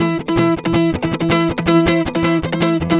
Track 16 - Guitar 02.wav